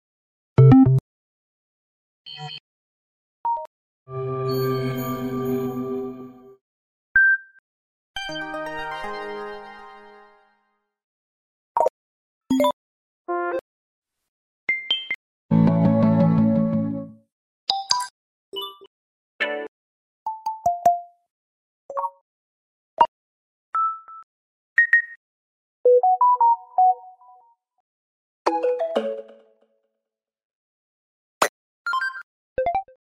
🎵 SAMSUNG GALAXY FLIP 3 NOTIFICATION SOUNDS 🎵